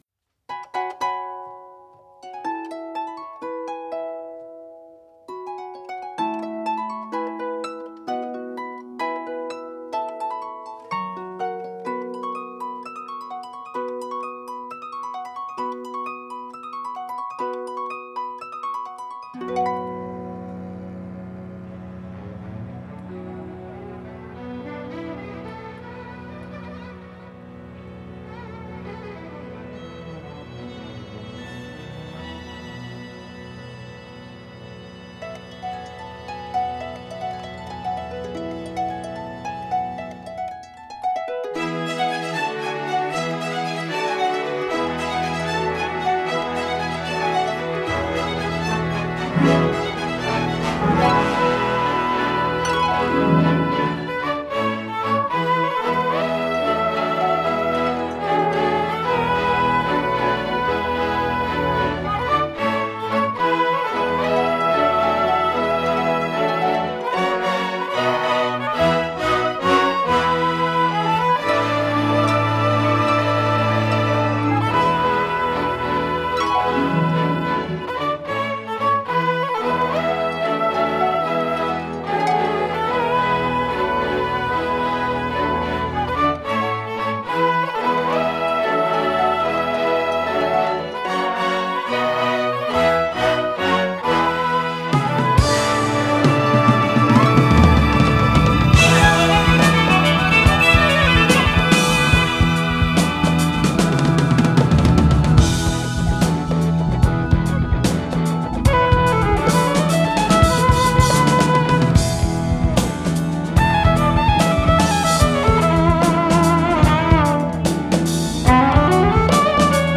Celtic fusion violinist